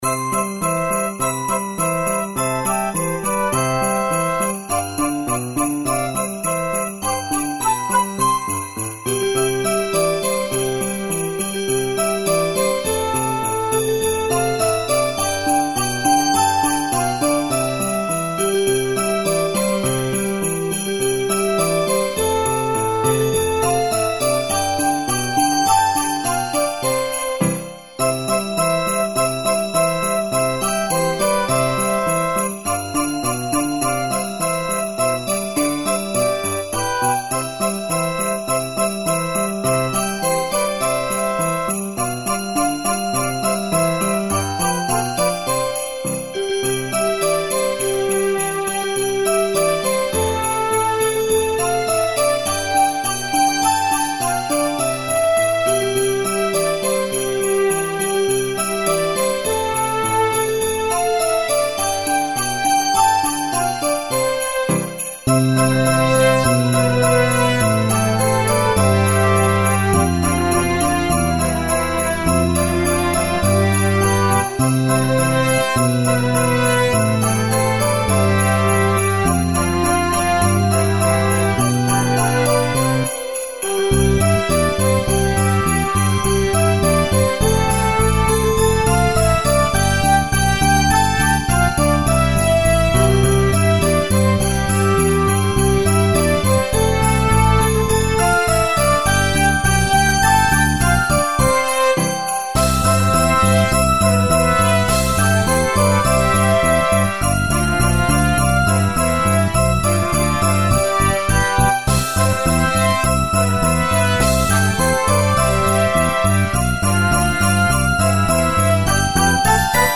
Christmas Karaoke
Canción navideña, Estados Unidos